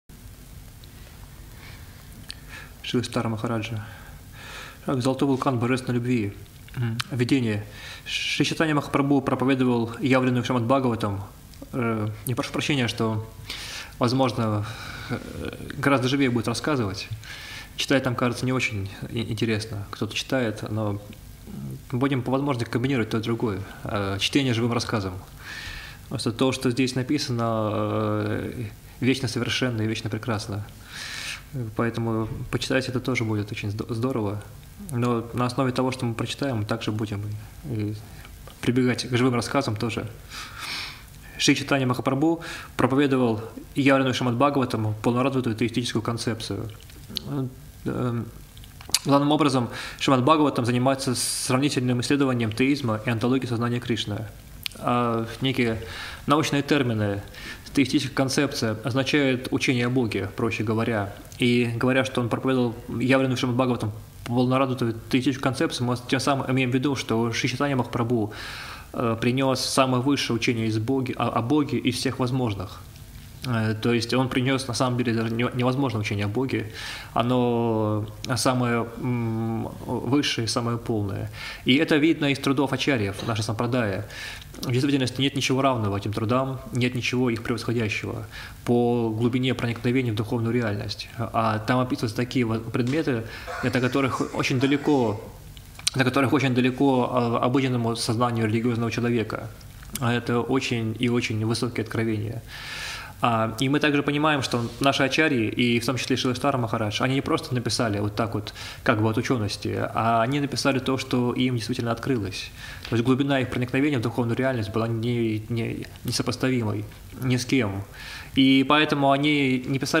Москва, Кисельный